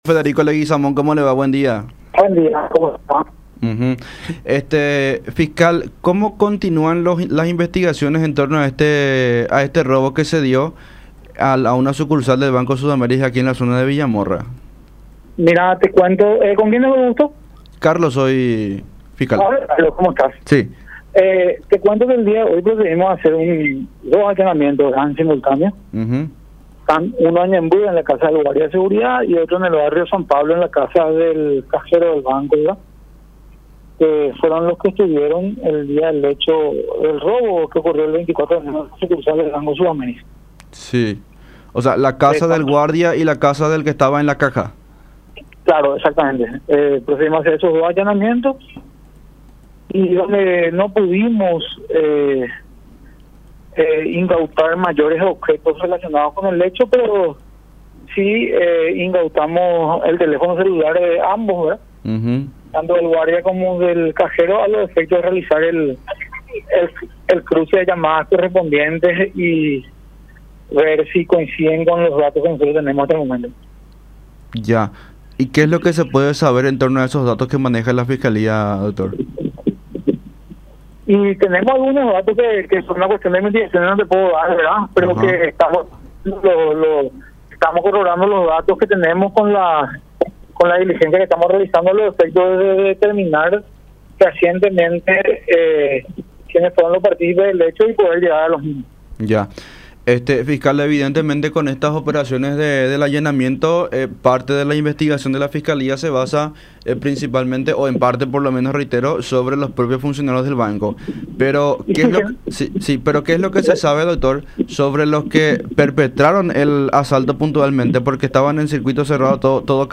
El fiscal Federico Leguizamón, interviniente en el caso y quien encabezó los operativos, comentó que, conforme avanzan las investigaciones, han encontrado elementos que hacen sospechar que todo el personal de la sucursal haya estado de alguna manera involucrado, aunque prefirió no ahondar en los datos.
11-FISCAL-FEDERICO-LEGUIZAMÓN.mp3